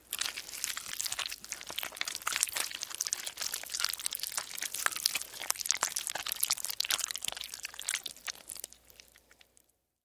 Звуковое полное покрытие тела масляным соусом